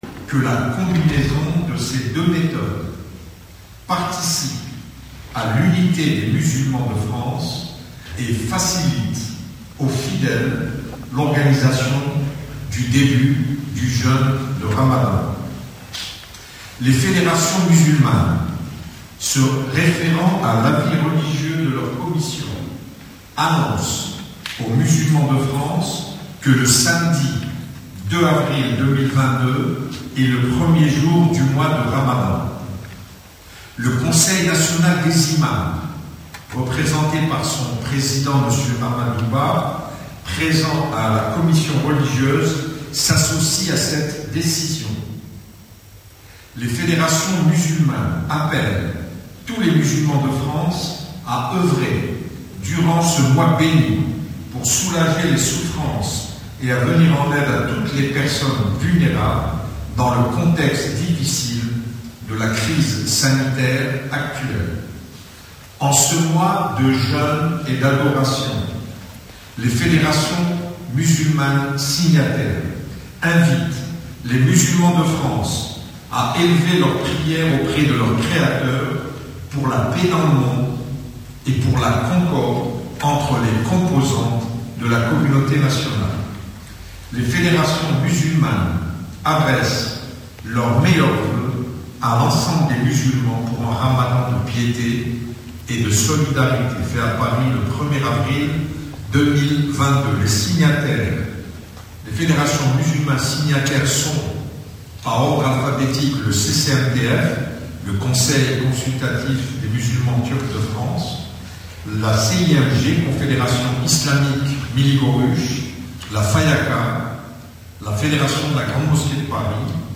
C’est ce qu’a annoncé le recteur de la Grande Mosquée de Paris, Chems Eddine Hafiz en direct sur Radio Orient.